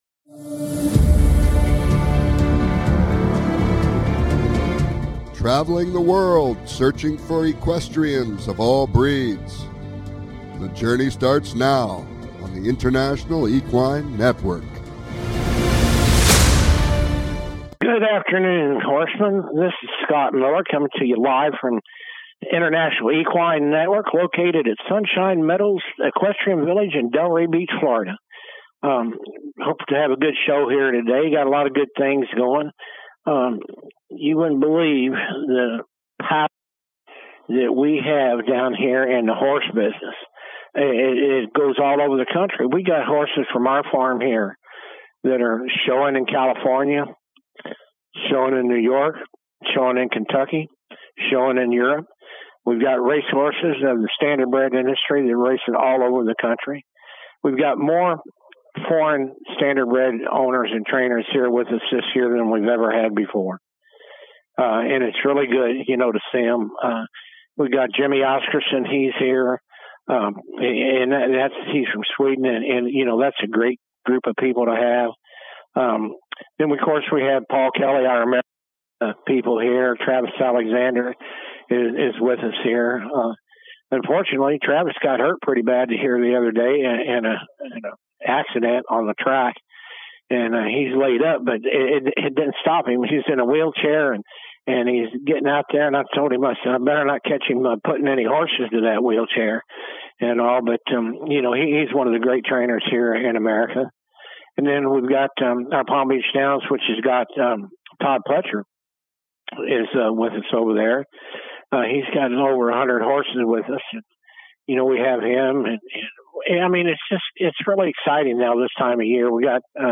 Talk Show
This show will bring you the inside scoop! Calls-ins are encouraged!